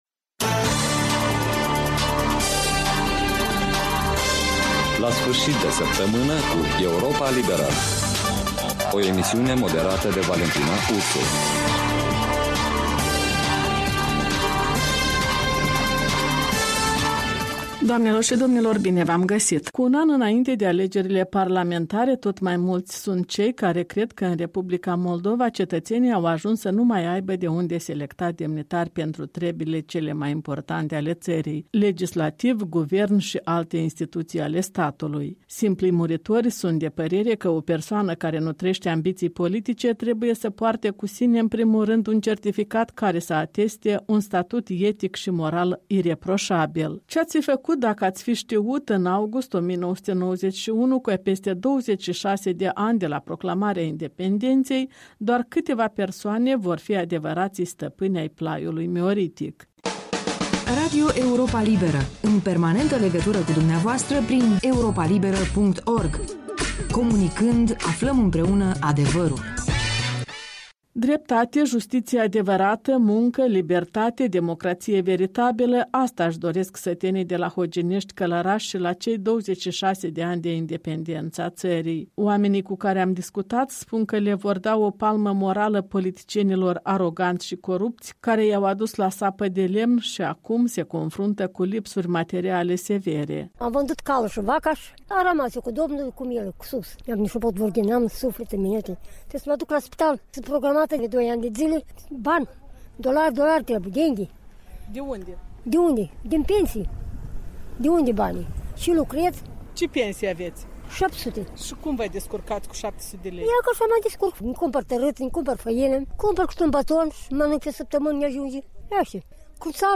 în dialog cu locuitori din Hoginești